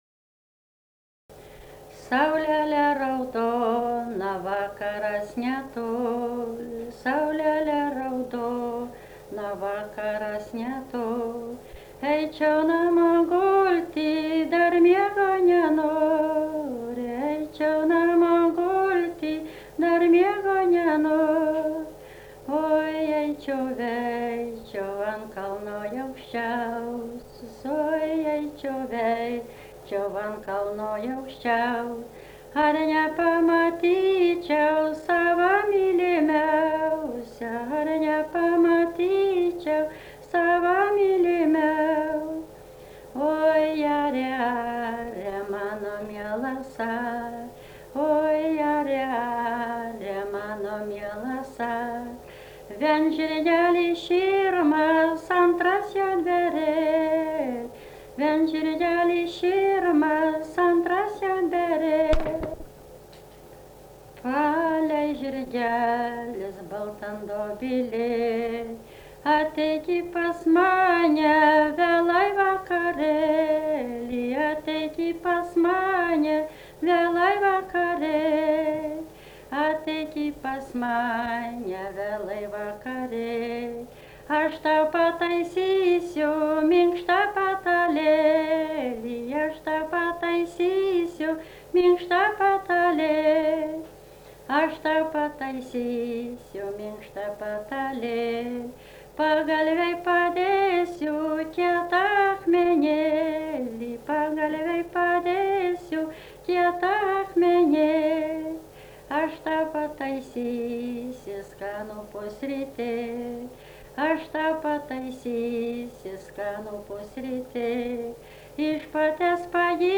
daina
Čypėnai
vokalinis